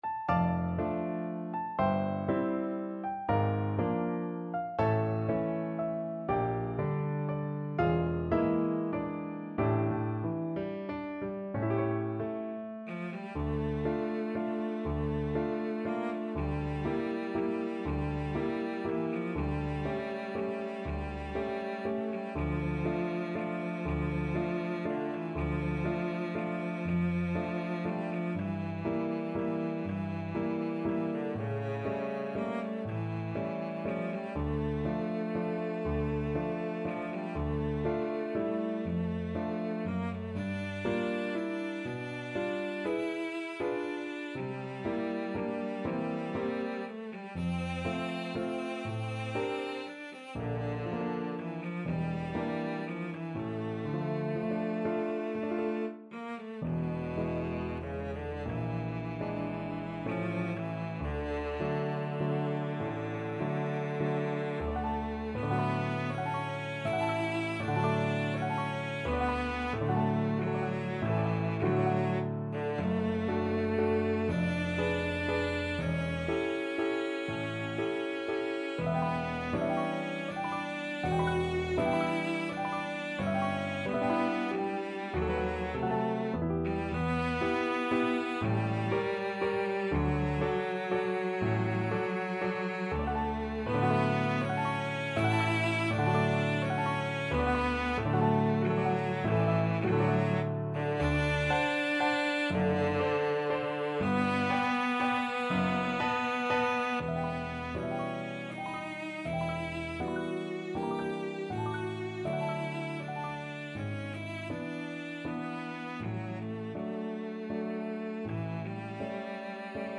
3/4 (View more 3/4 Music)
~ = 120 Lento
Classical (View more Classical Cello Music)